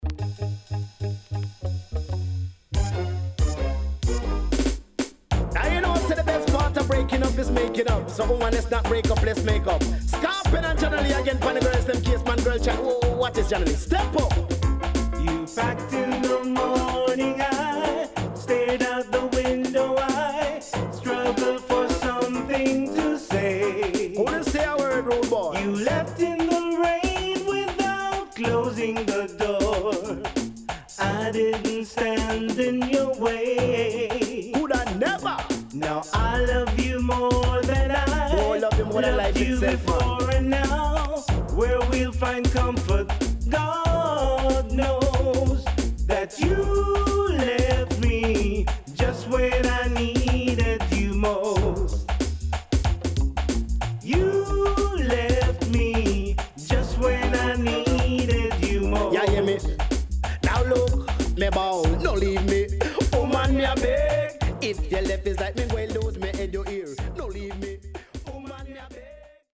HOME > 90's〜  >  COMBINATION  >  RECOMMEND DANCEHALL
CONDITION SIDE A:VG(OK)〜VG+
SIDE A:所々チリノイズがあり、少しプチノイズ入ります。